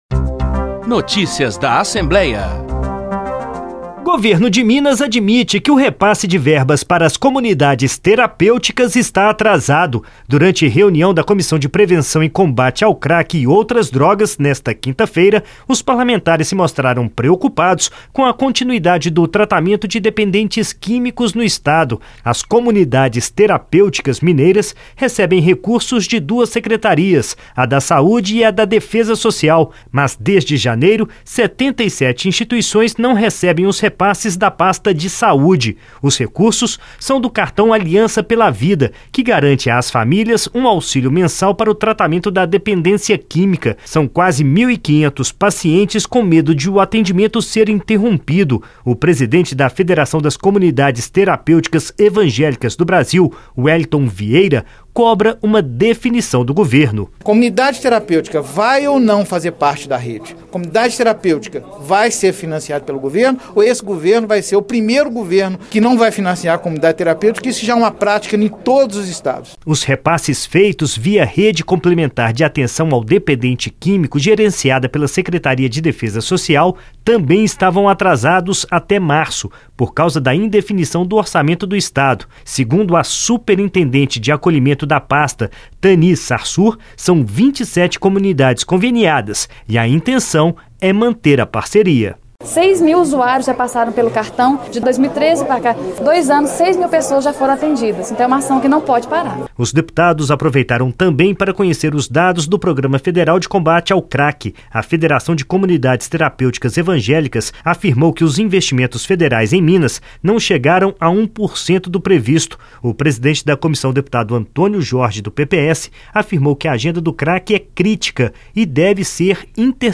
Notícias da Assembleia